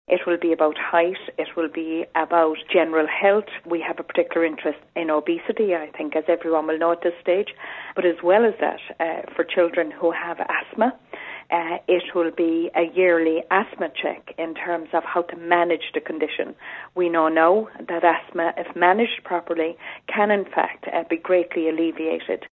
Minister for Primary Care Kathleen Lynch says youngsters will receive two health checks over the course of the plan………………..